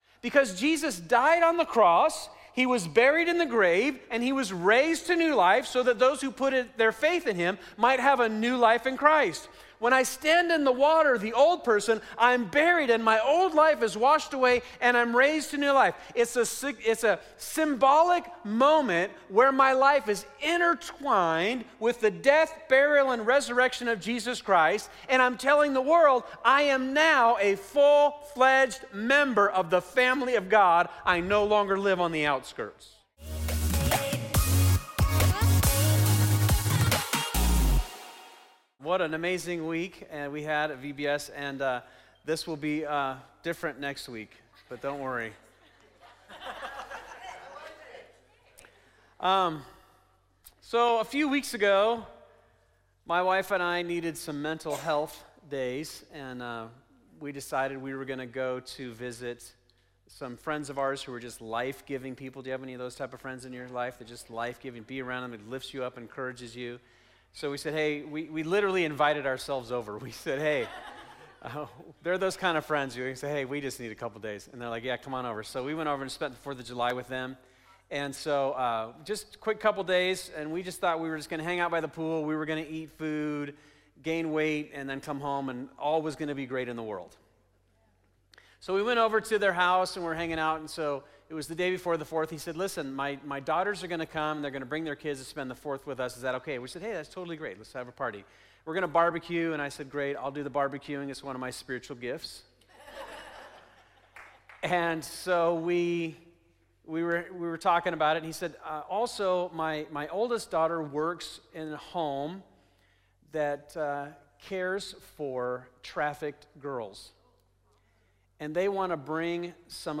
This is part 13 of "The After Party," our series at Fusion Christian Church on the book of Acts.